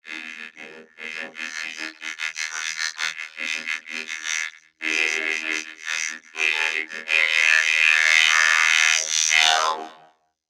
Robot Malfunction Sneezing Sound
Cartoon Funny Robot Sneezing sound effect free sound royalty free Funny